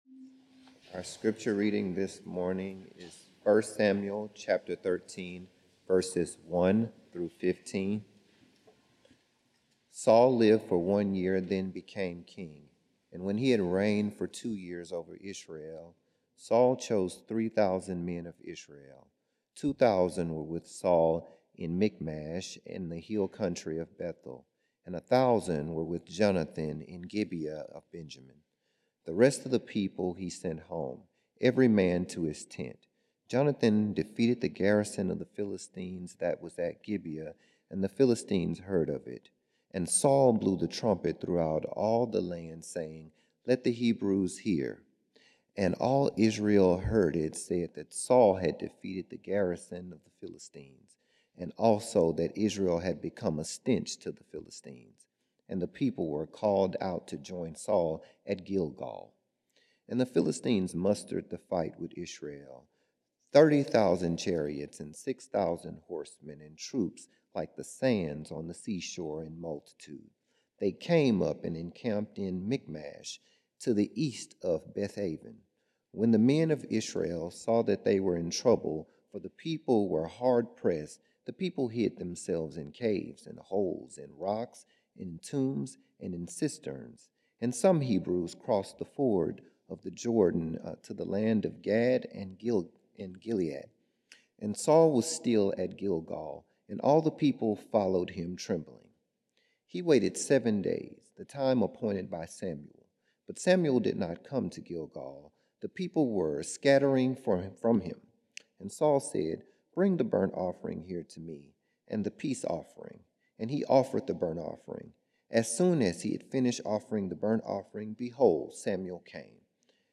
Beeson Divinity School Chapel Services Stay in Your Lane, 1 Samuel 13:1-15